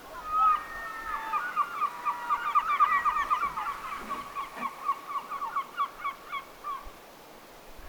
lisää harmaalokin ääntelyä
paikallisia_harmaalokkeja_lisaa.mp3